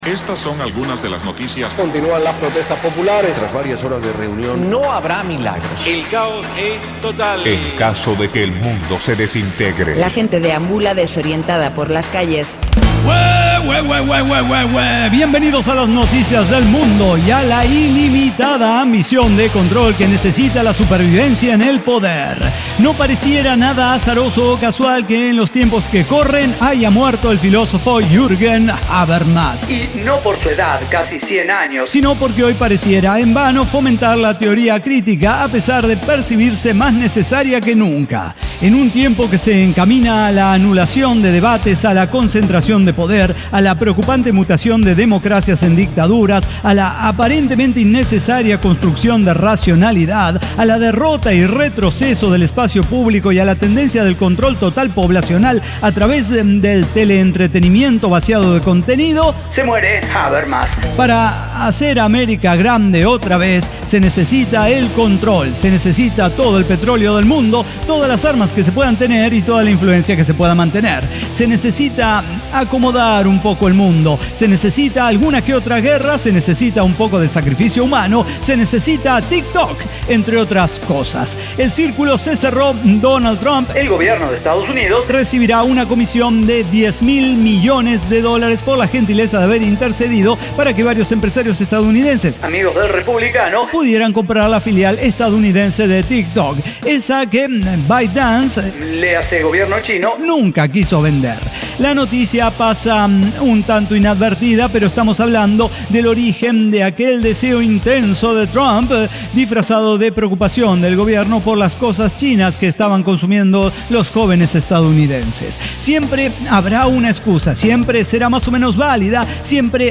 NO AI: ECDQEMSD Podcast no utiliza ninguna inteligencia artificial de manera directa para su realización. Diseño, guionado, música, edición y voces son de nuestra completa intervención humana.